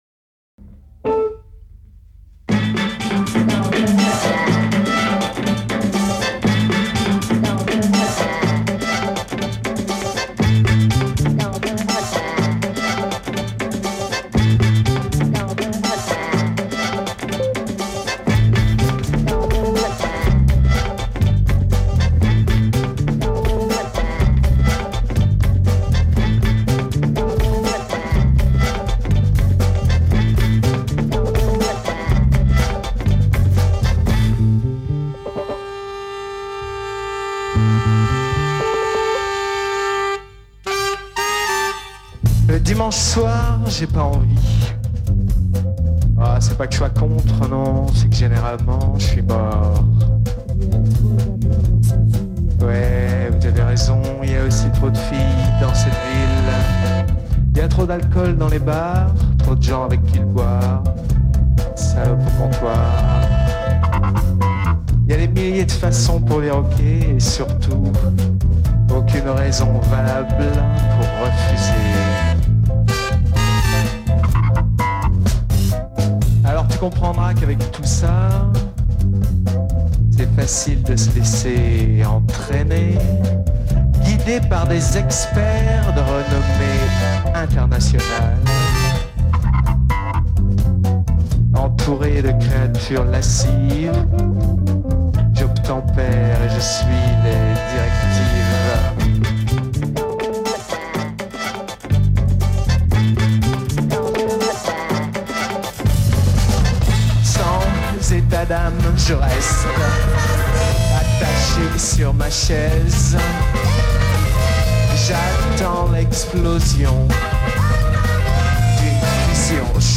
enregistrée le 12/03/2001  au Studio 105